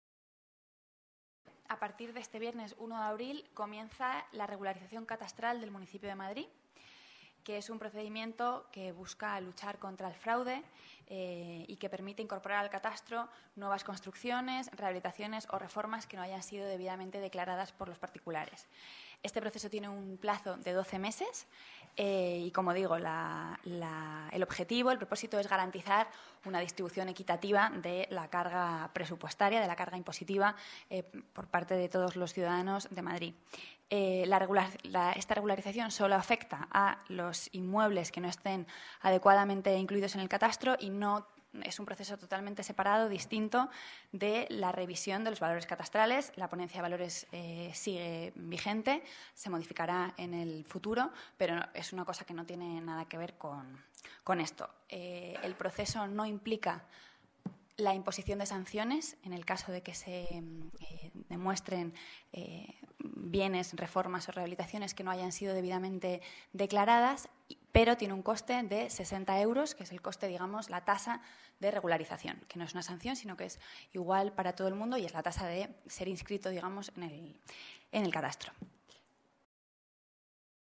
Nueva ventana:Rita Maestre, portavoz Gobierno Municipal